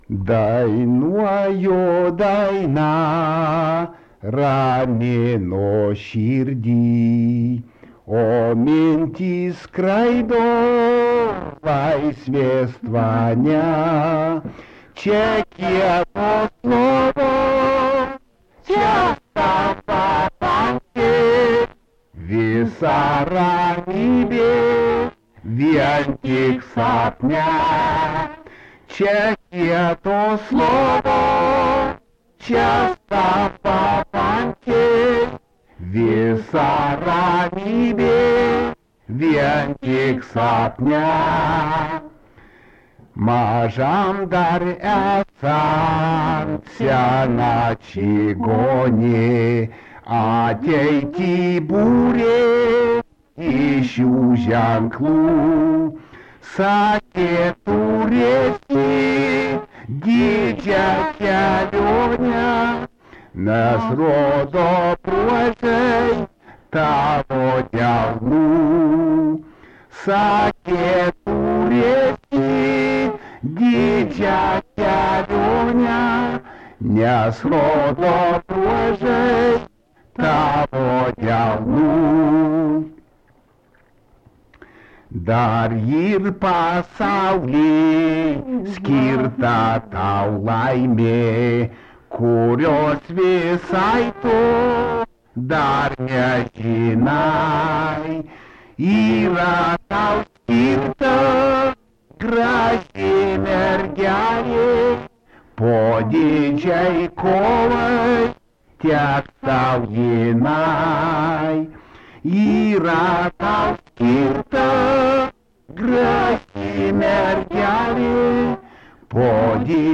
Subject romansas
Erdvinė aprėptis Raitininkai
Atlikimo pubūdis vokalinis